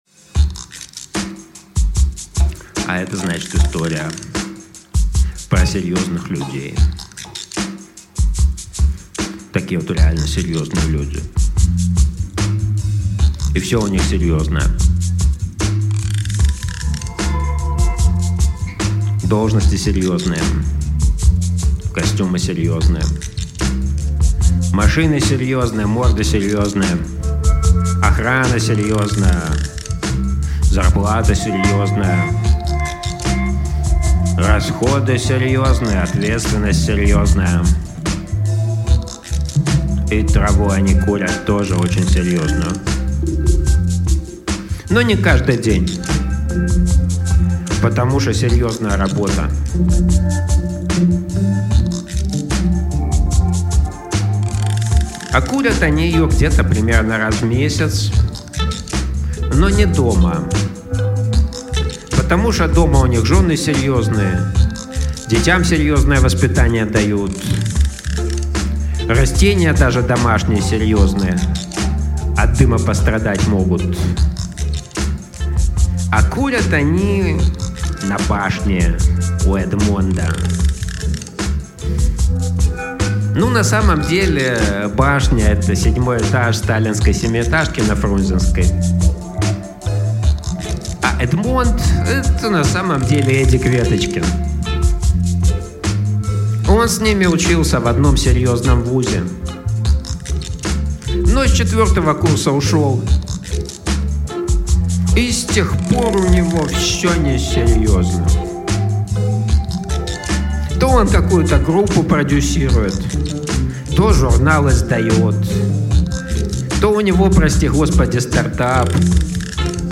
Аудиокнига Про серьёзных людей | Библиотека аудиокниг